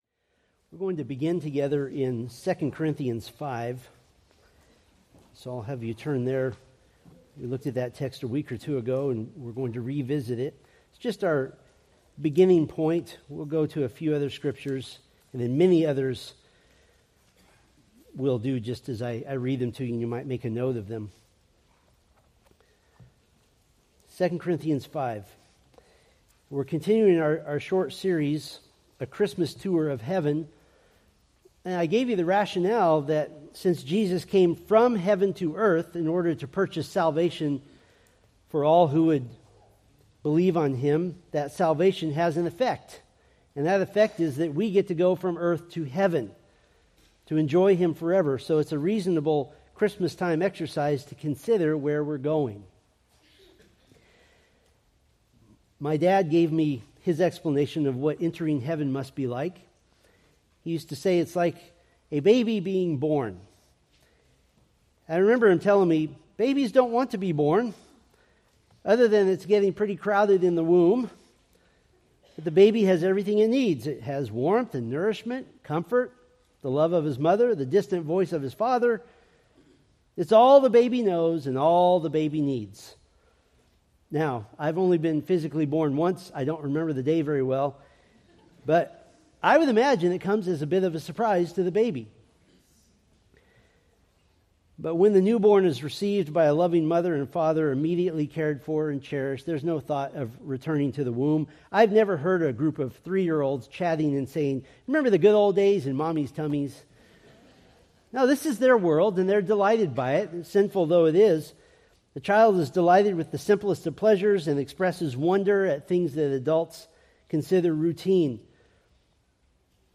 Preached December 22, 2024 from Selected Scriptures